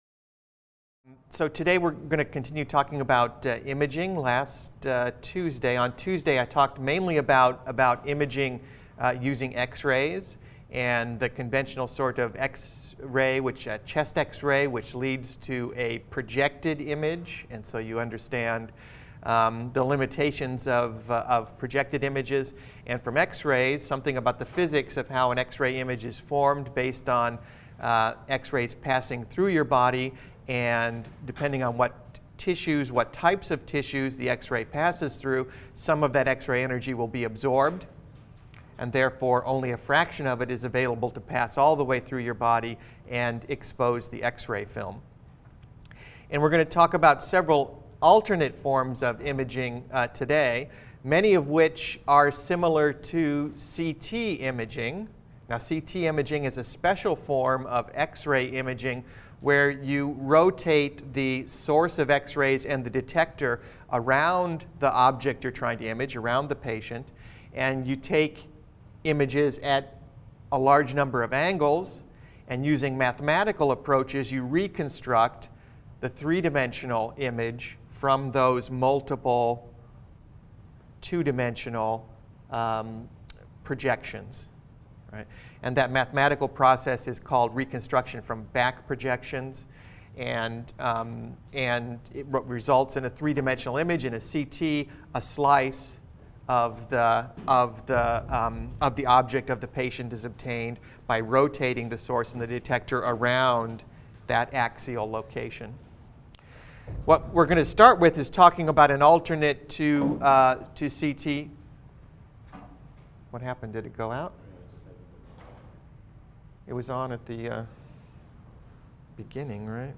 BENG 100 - Lecture 21 - Bioimaging (cont.)